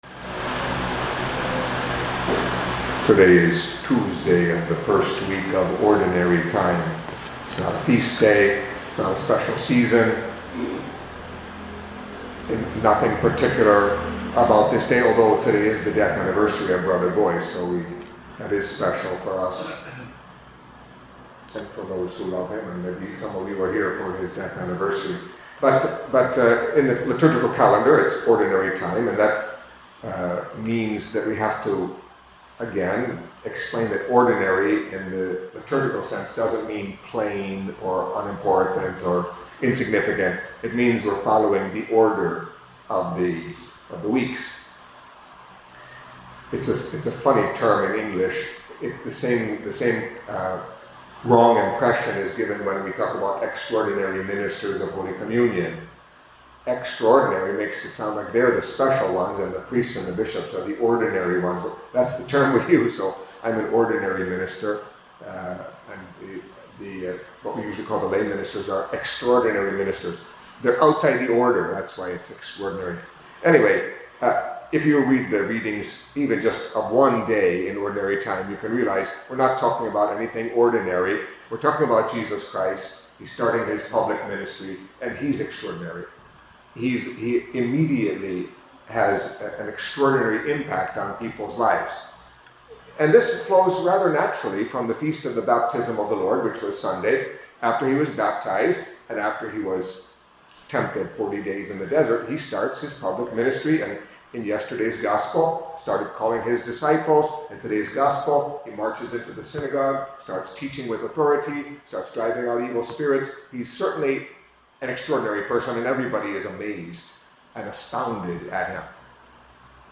Catholic Mass homily for Tuesday of the First Week in Ordinary Time